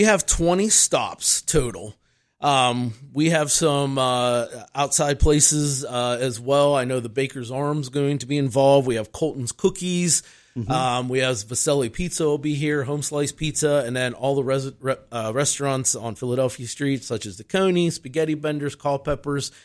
Taste and Tour is a fundraiser event put on by Downtown Indiana, Inc. in partnership with First Commonwealth Bank.